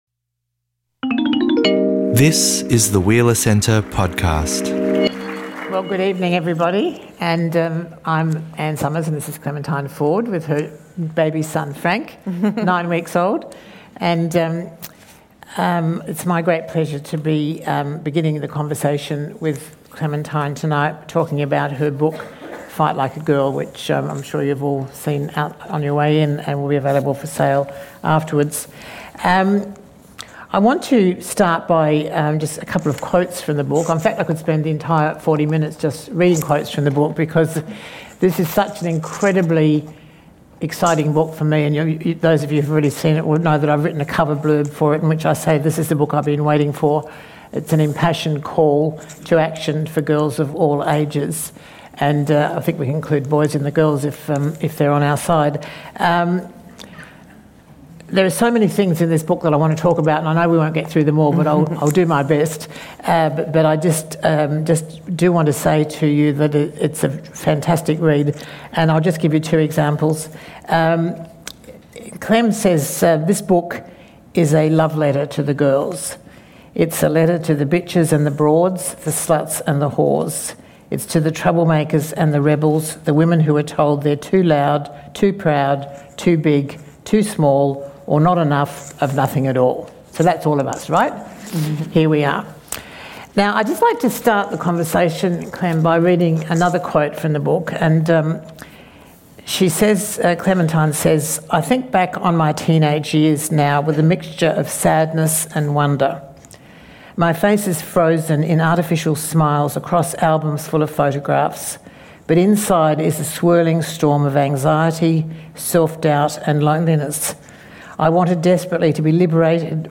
In this conversation – as part of our HEY GIRL series – the pair focus on the notion of girlhood and discuss how experiences in their early lives shaped their ideas around feminism. How has the feminist fight changed? Has progress stagnated or are we in a moment of renewed feminist vigour and optimism?